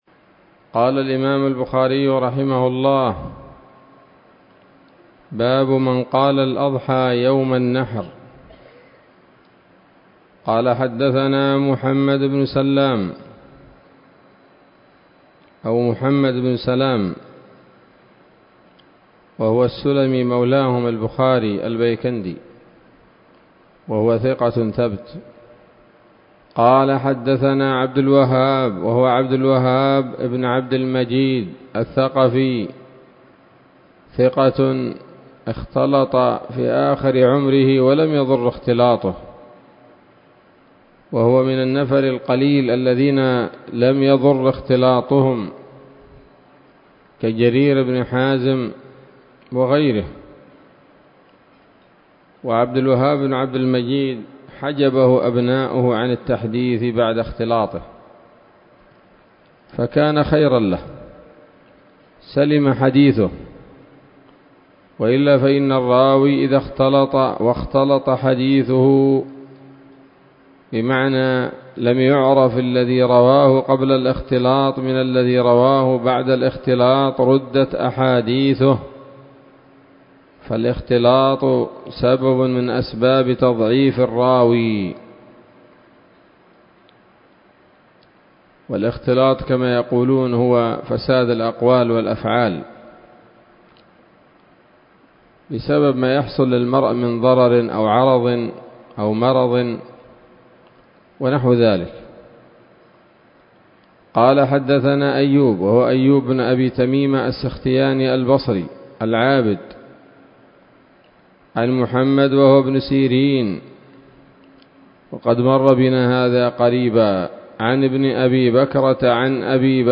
الدرس الخامس من كتاب الأضاحي من صحيح الإمام البخاري